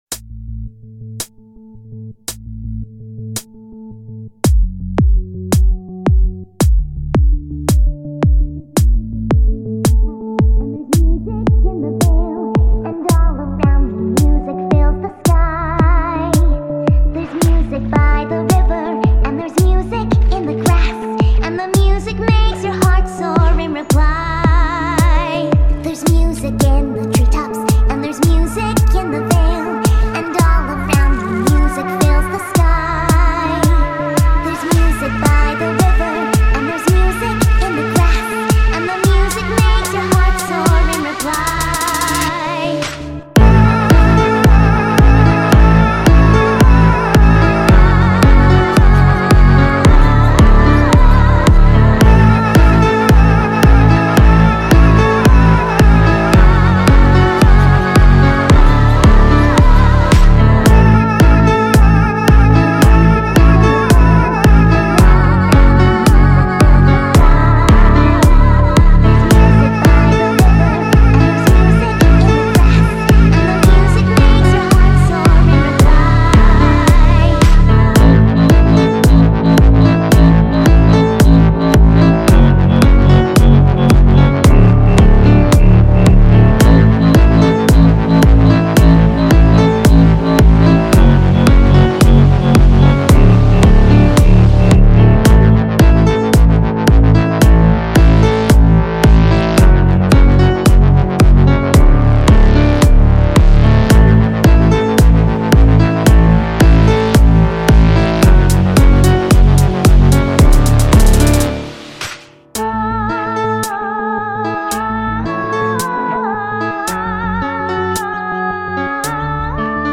Liquid Dubstep Remix